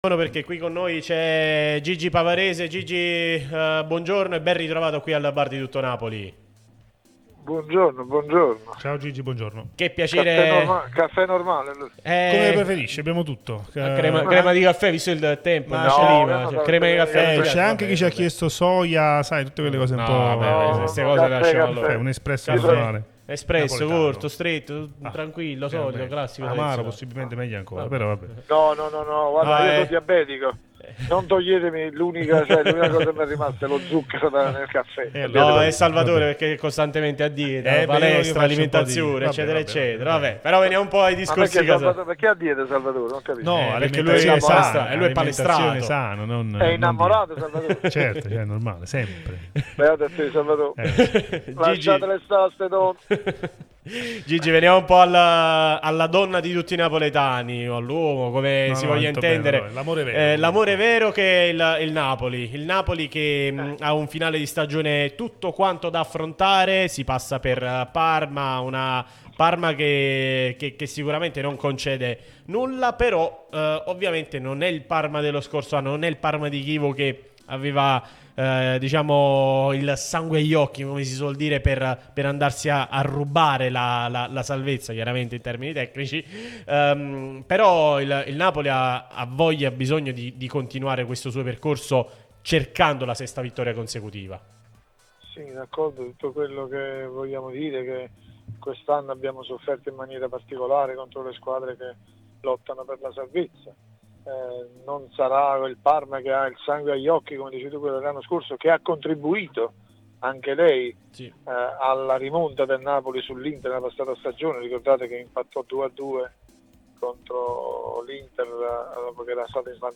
è intervenuto sulla nostra Radio Tutto Napoli